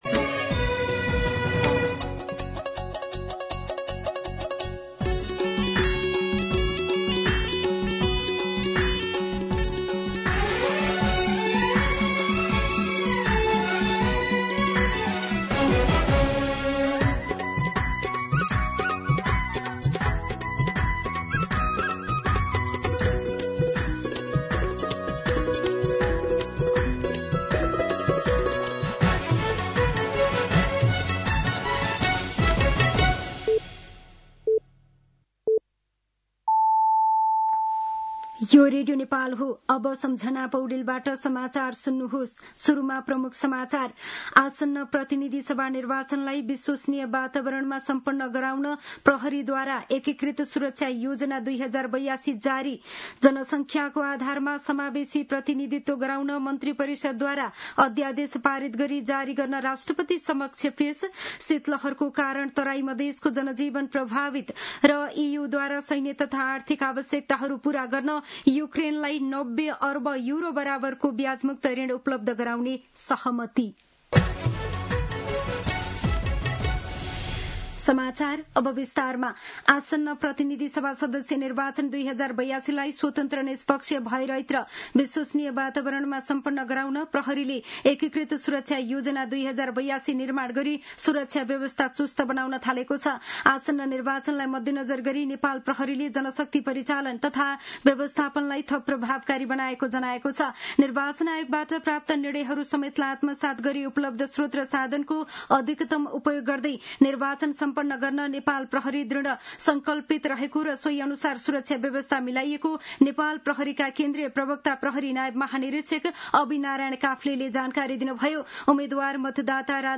दिउँसो ३ बजेको नेपाली समाचार : ४ पुष , २०८२
3-pm-News-9-4.mp3